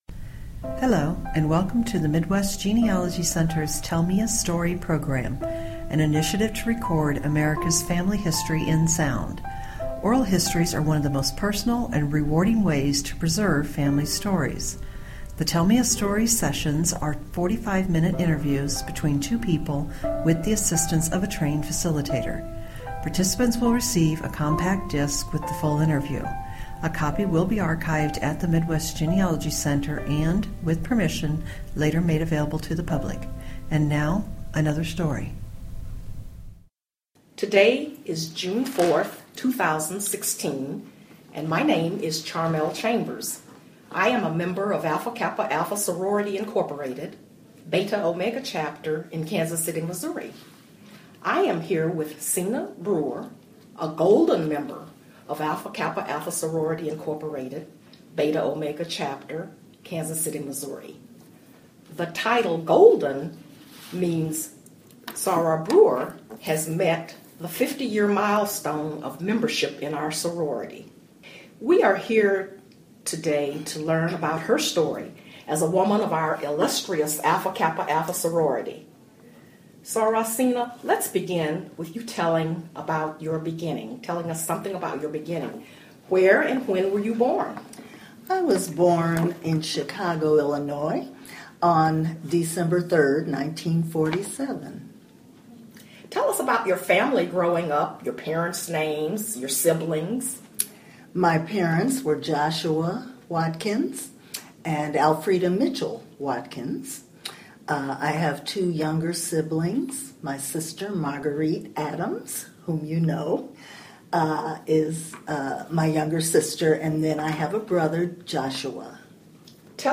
Oral history
Stereo